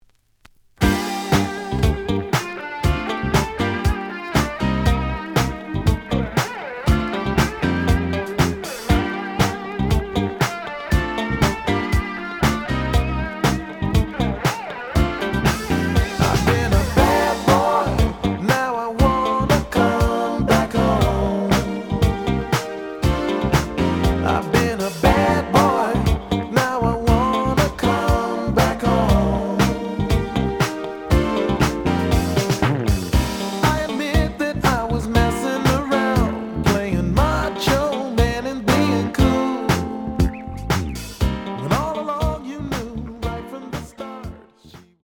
試聴は実際のレコードから録音しています。
The audio sample is recorded from the actual item.
●Format: 7 inch
●Genre: Funk, 80's / 90's Funk